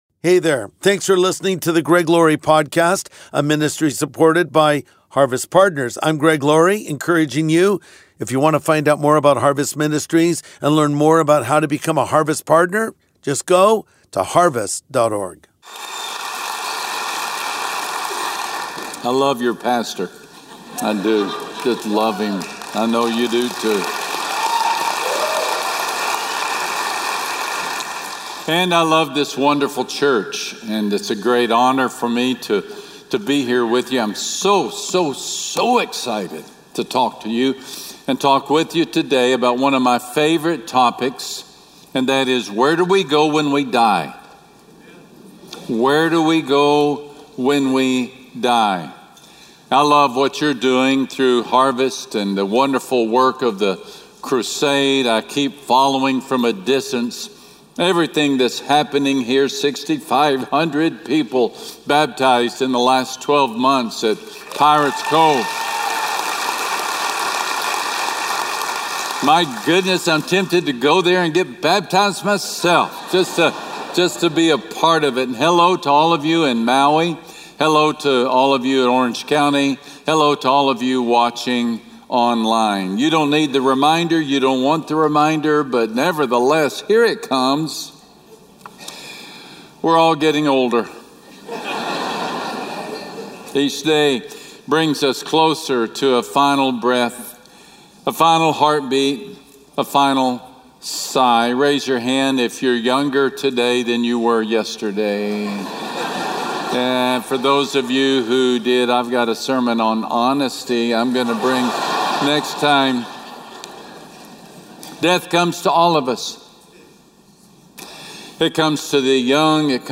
What Happens When We Die? | Sunday Message With Max Lucado
And today, pastor and author Max Lucado addresses this spiritual question and helps ease any fears we have relating to the afterlife.